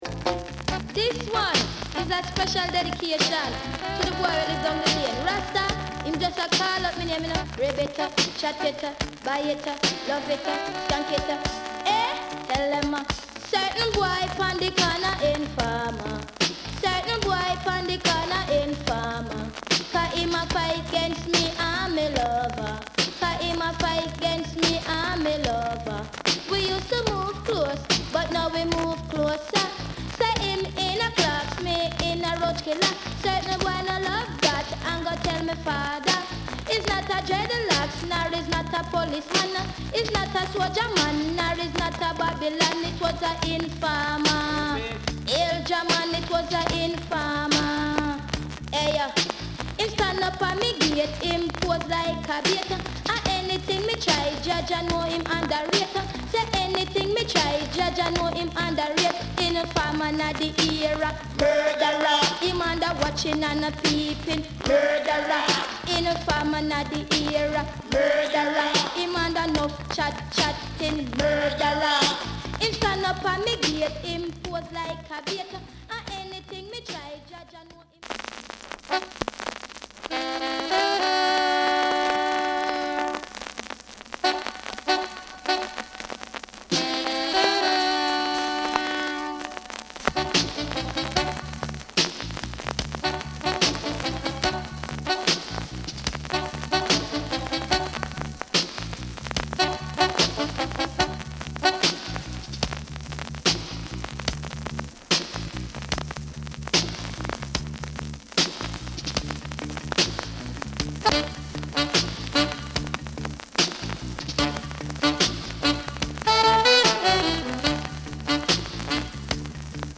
Genre: Dancehall
ジャマイカで初めて女性DJとして#1シングル・#1アルバムを同時達成した歴史的な一枚。